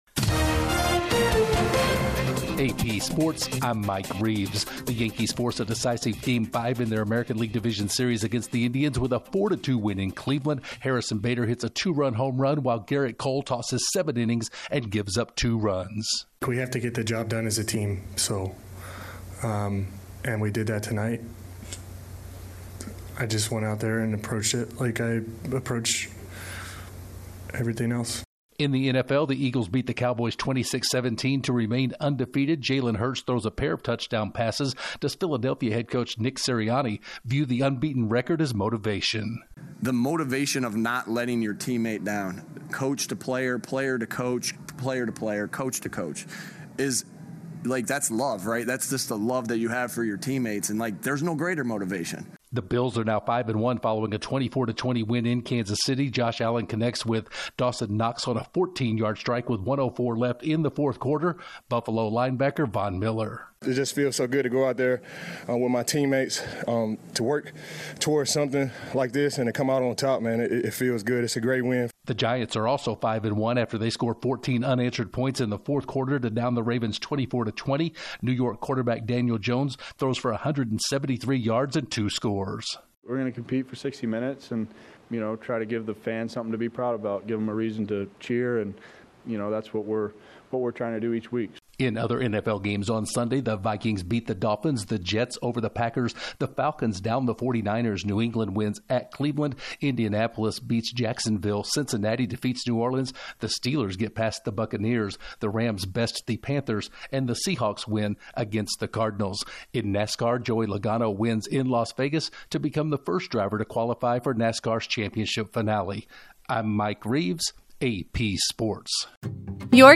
The Yankees stay alive, the Eagles win again, the Bills pick up a big win in Kansas City, and NASCAR has their first qualifier for the end of the season finale. Correspondent